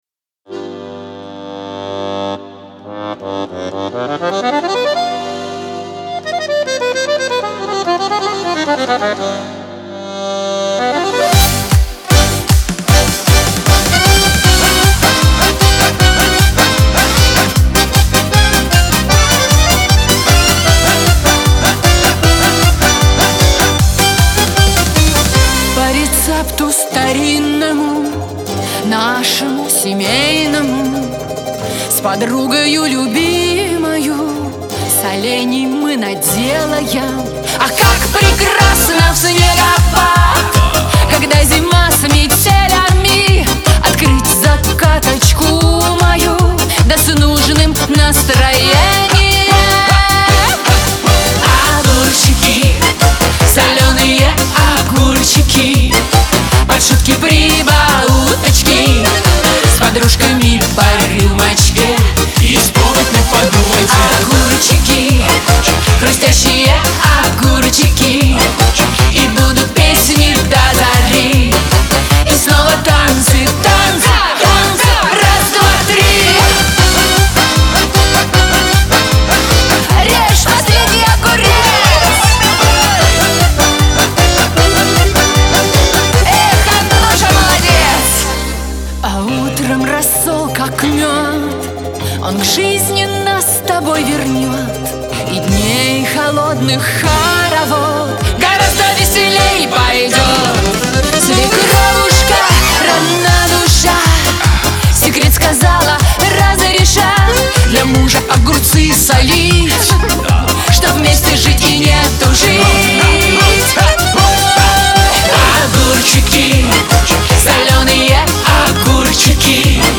Шансон , Веселая музыка